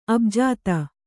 ♪ abjāta